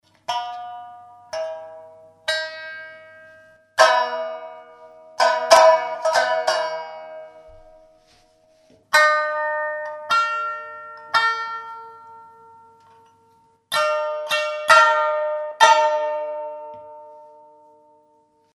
Die Laute Subü
Die bundlose Langhalslaute kennt drei oder vier Saiten, und manchmal erscheinen auch größere Modelle mit Bünden, die jedoch eher wie Vorläufer der heute im Norden Thailands so beliebten Phin erscheinen.
Die Saiten werden mit einem Holzkegel als Plektrum engezupft, und das Instrument ist für seine Größe erstaunlich laut!
Der kleine Resonanzkasten ist mit Ziegenhaut überspannt und erinnert an eine kleine Handtrommel.
* Klangbeispiel (MP3, 296 KB) *